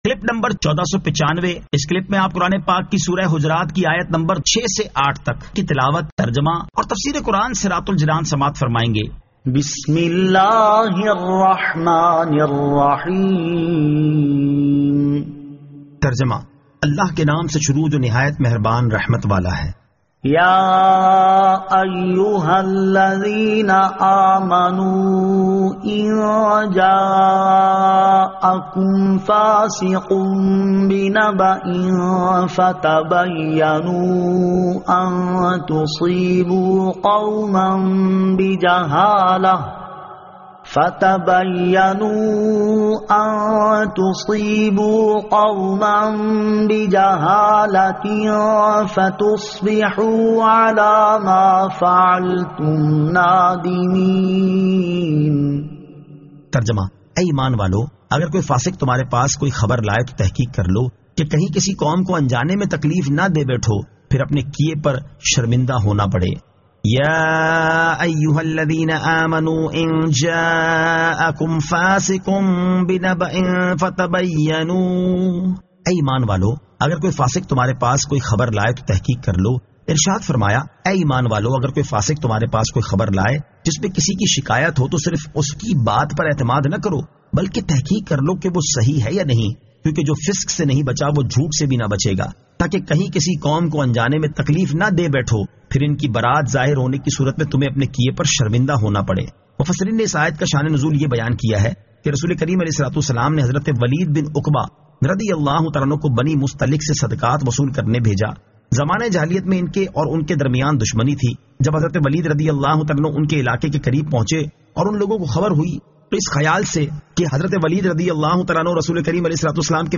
Surah Al-Hujurat 06 To 08 Tilawat , Tarjama , Tafseer